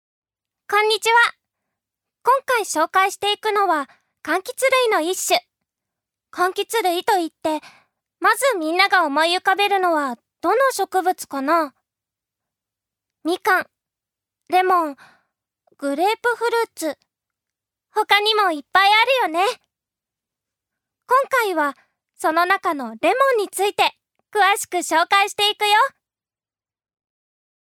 ジュニア：女性
ナレーション４